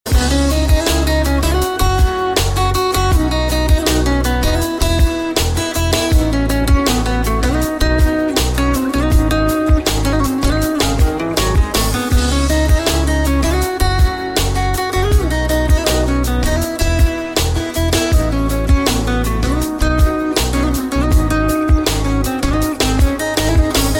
Guitar sounds ringtone free download